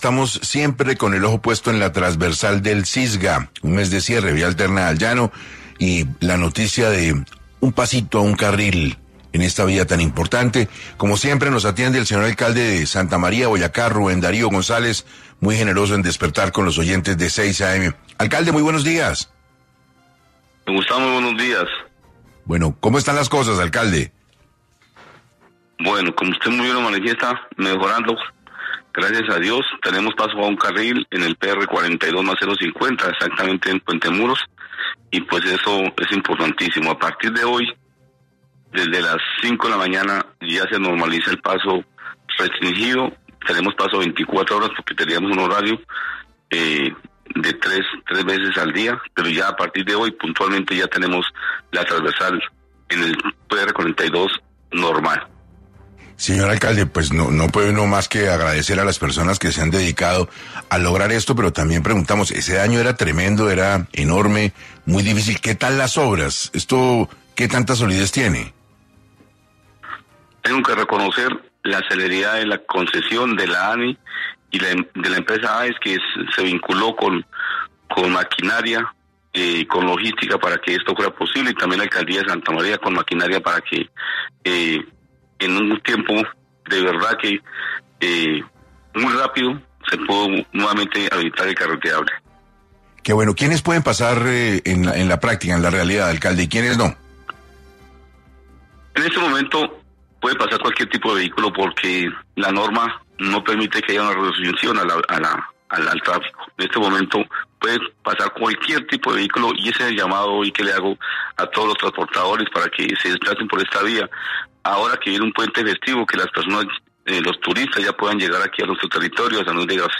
Rubén Darío González, alcalde de Santa María, Boyacá, habló en 6AM cómo será la habilitación parcial del paso en la Transversal del Sisga